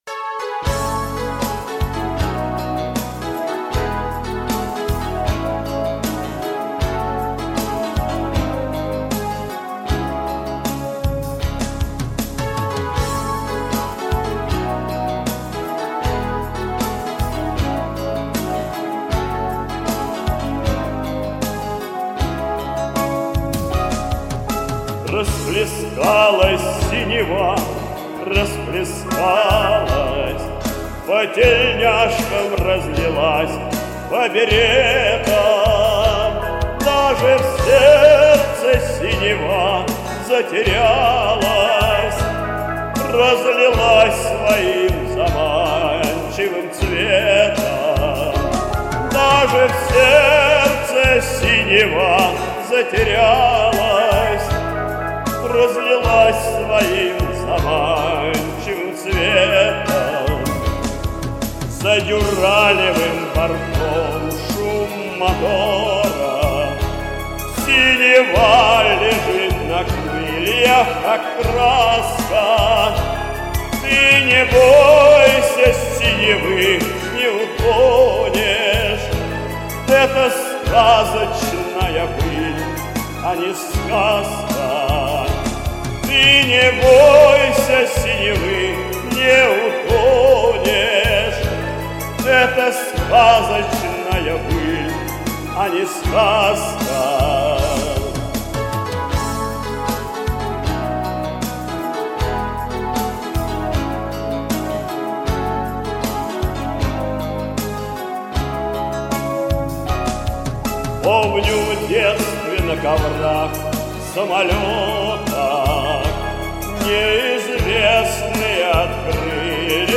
Добротный, красивый голос, соответствующий этой песне.
У первого пафосно, у второго по-кабацки.